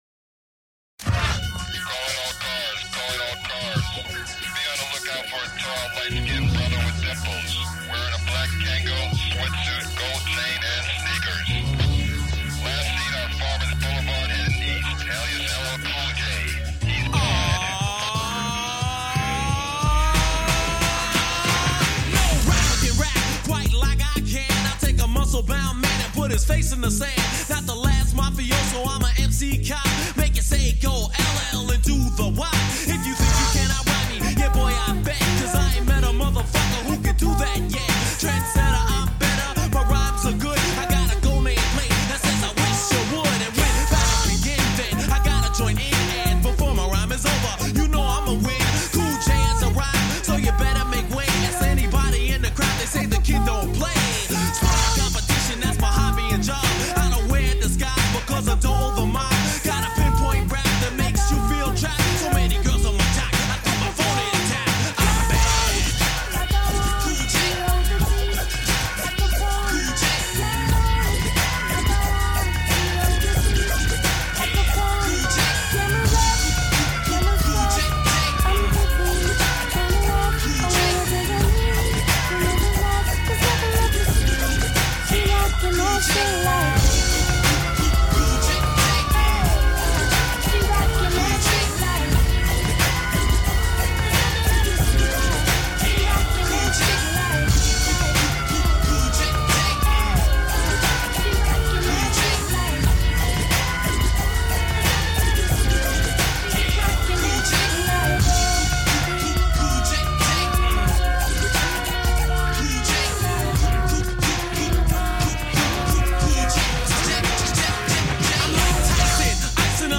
Old School Hip-Hop